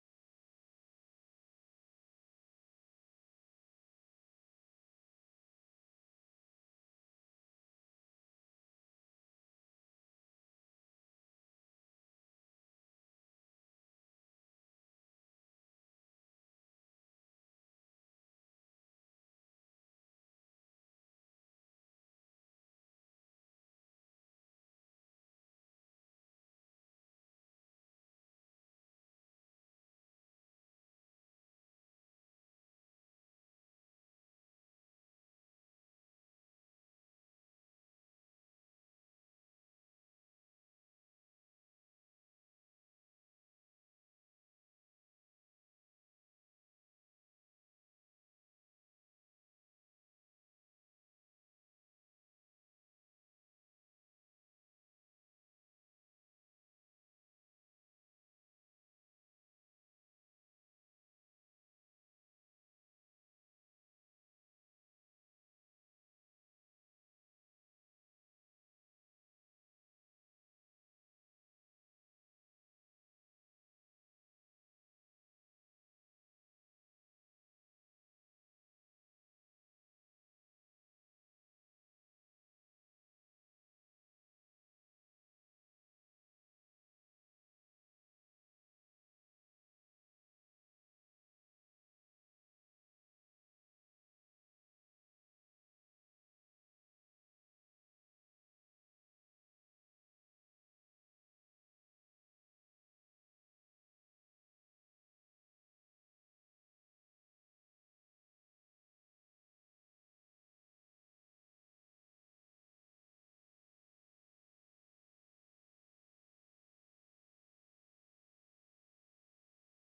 This is a sermon on Luke 1:67-80.